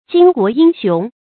注音：ㄐㄧㄣ ㄍㄨㄛˊ ㄧㄥ ㄒㄩㄥˊ
讀音讀法：